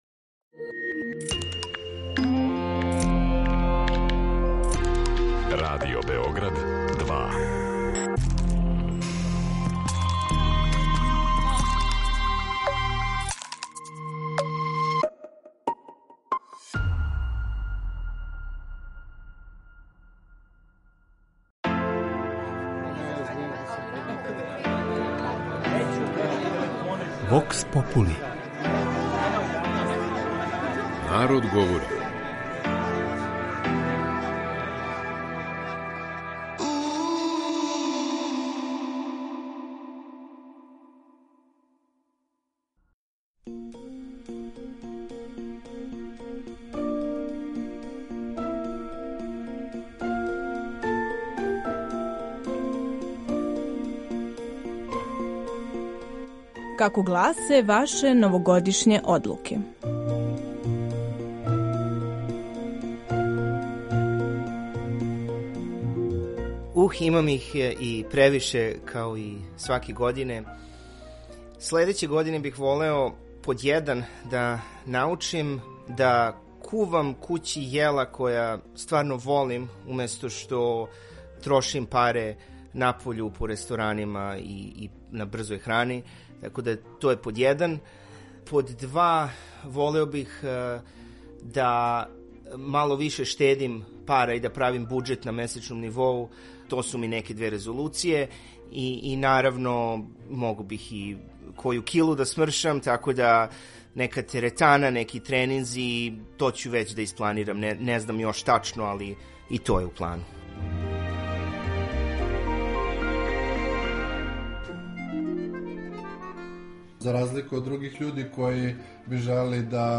То је права прилика за доношење великих одлука, прављење планова и зацртавање циљева које желимо да остваримо у следећих 365 дана. Наши суграђани су нам саопштили своје новогодишње резолуције, очекивања и надања која везују за годину која је пред нама.